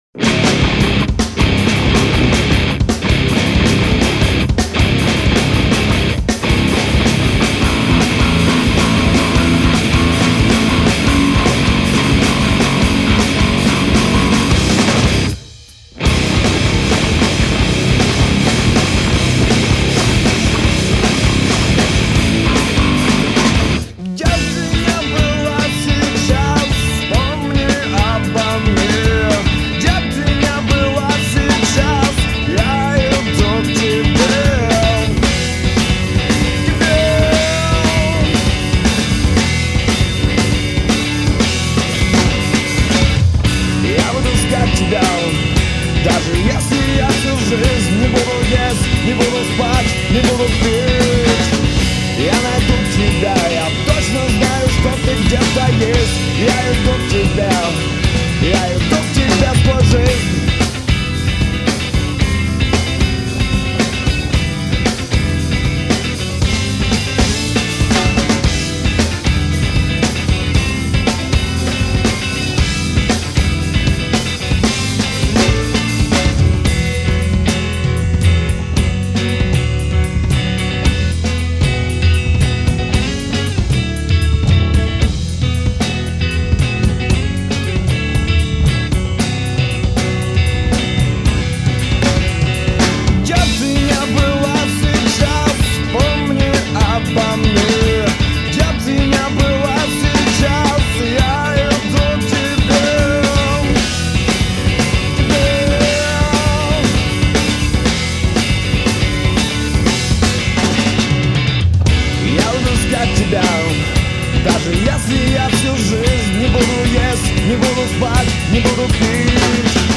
з.ы - вокал мне лично не понравился совсем
Плюс ко всему голос совершенно невыразительный какой-то...